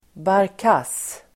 Ladda ner uttalet
barkass.mp3